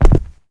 Land.wav